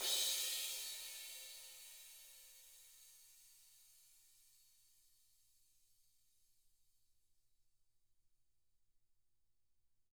Index of /90_sSampleCDs/ILIO - Double Platinum Drums 2/Partition F/SWISH RIDED